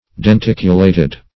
Search Result for " denticulated" : The Collaborative International Dictionary of English v.0.48: Denticulate \Den*tic"u*late\, Denticulated \Den*tic"u*la`ted\, a. [L. denticulatus, fr. denticulus.
denticulated.mp3